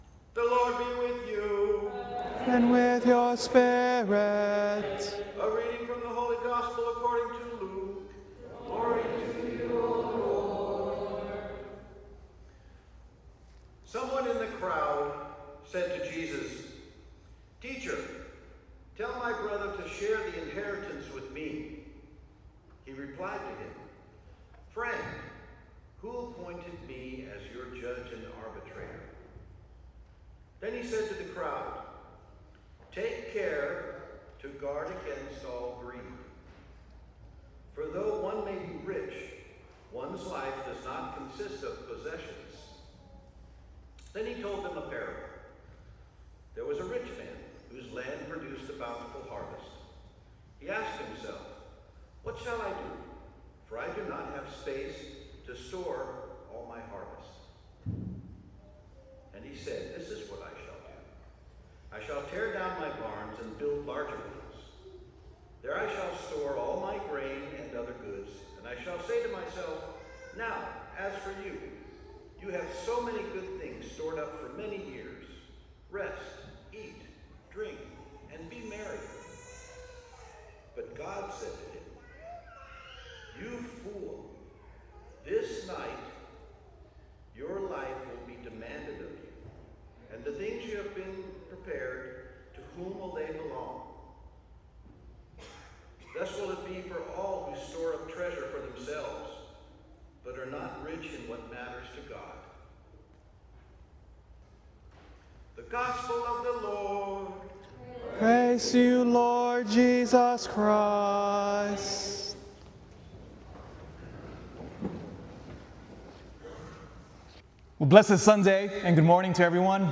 ***BONUS Meditation Hymn at the end from St. Mary's beautiful choir***